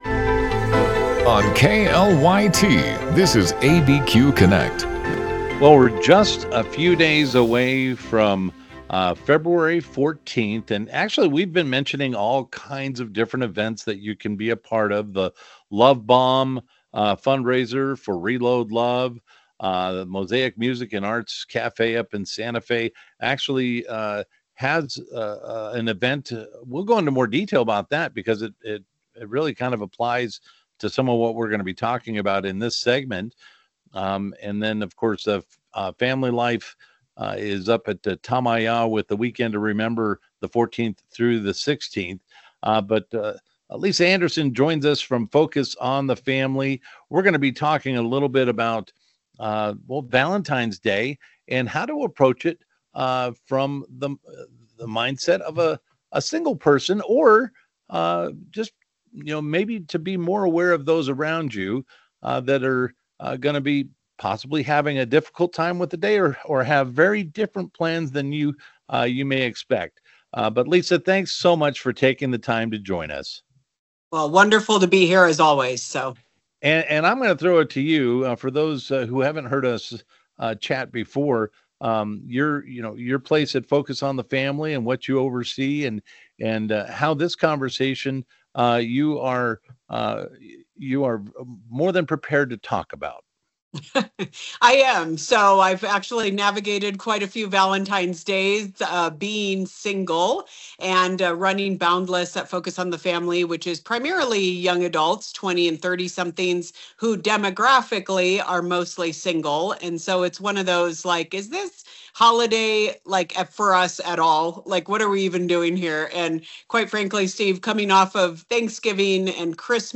Live, local and focused on issues that affect those in the New Mexico area. Tune in for conversations with news makers, authors, and experts on a variety of topics.